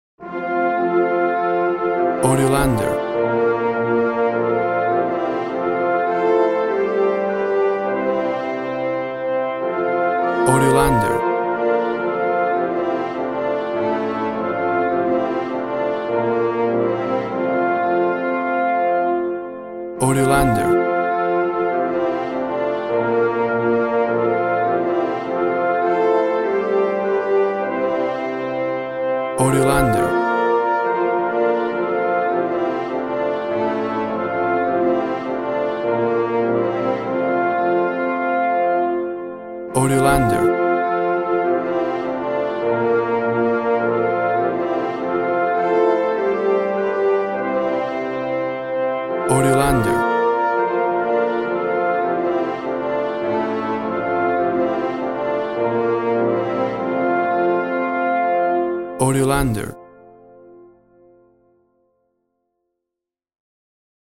A traditional brass band version
Tempo (BPM) 120